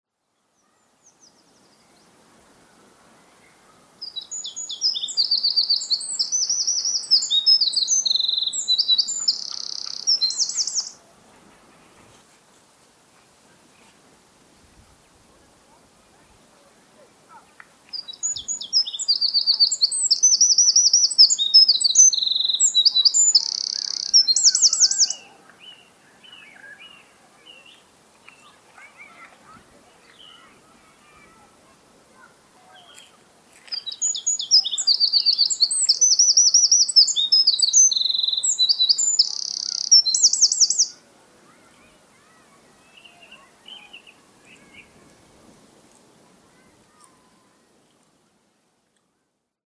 Art: Gjerdesmett (Troglodytes troglodytes)
• Winter Wren på engelsk
Sang: Til tross for den beskjedne kroppsstørrelsen har den en kraftig og aksellererende sang med et et raspende motiv midt i strofen. Denne sangen kan også tidvis høres om høsten og vinteren.